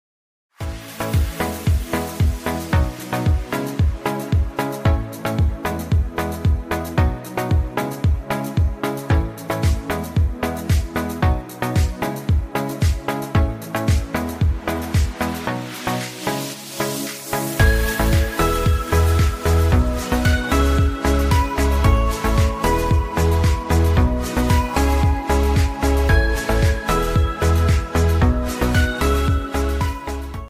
PVC double output pipe production line sound effects free download